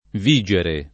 v&Jere] v.; vigo [v&go], vigi — difettivo, privo di pass. rem. e part. pass.; usato quasi solo nelle 3e persone sing. e pl. dei tempi semplici (vige, vigono, vigeva, vigevano, ecc.) e nel part. pres. vigente [viJ$nte], anche aggettivo — cfr. Vigevano